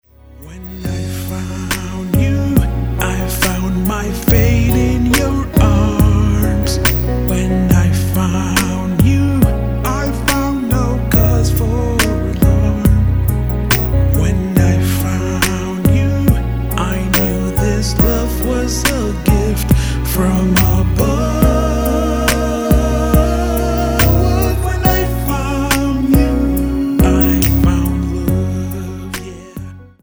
NOTE: Vocal Tracks 10 Thru 18